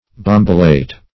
Bombilate \Bom"bi*late\